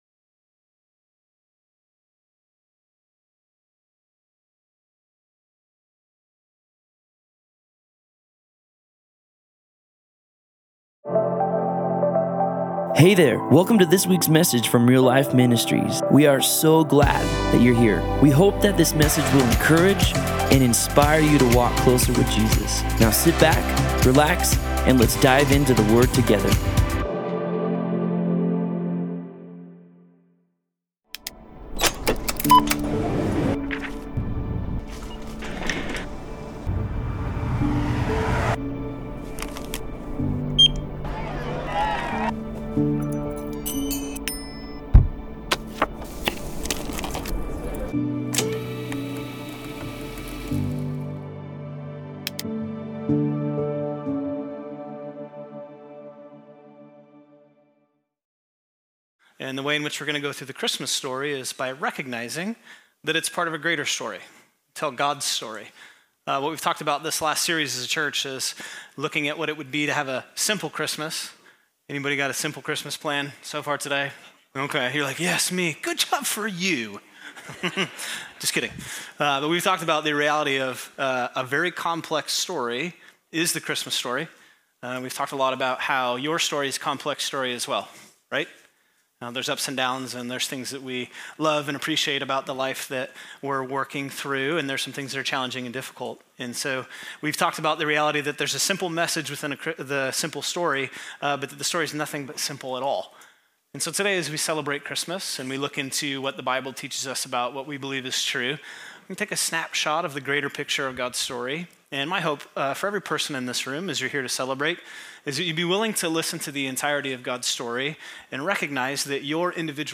Christmas+Eve+Service+Audio+|+CDA+Campus.mp3